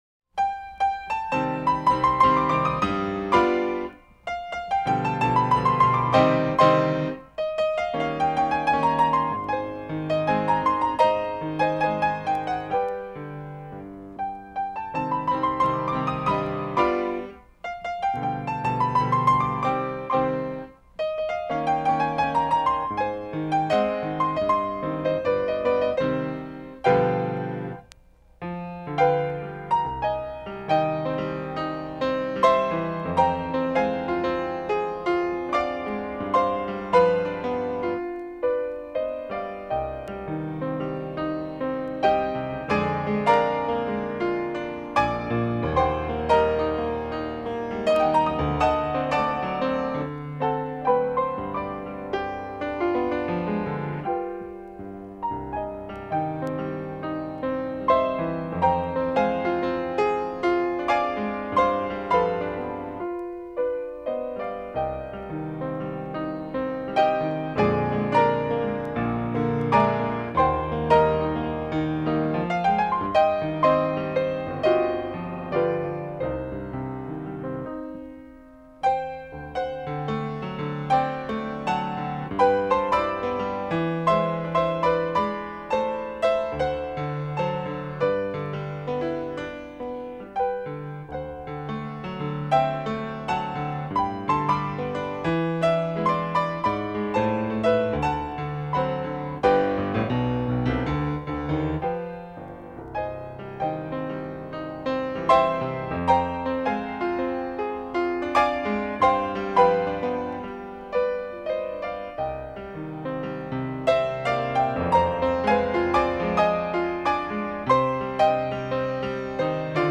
ICP/AD-23: Danzas de Rafael Duchesne